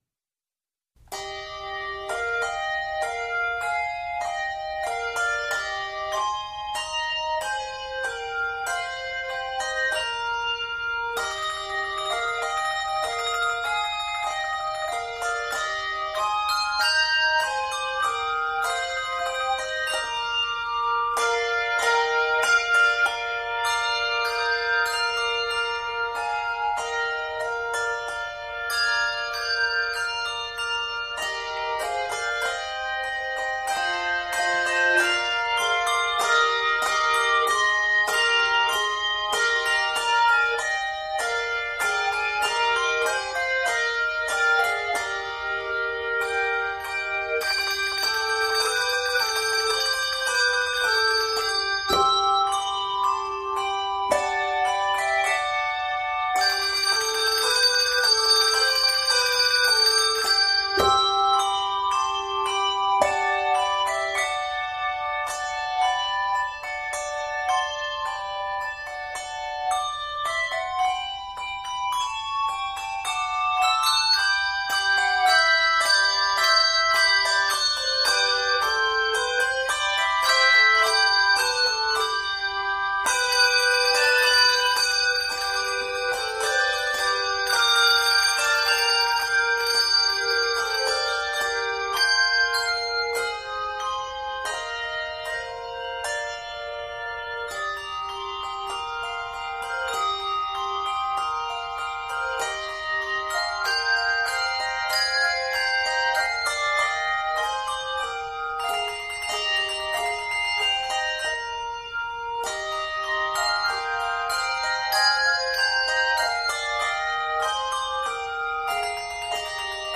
two-octave arrangements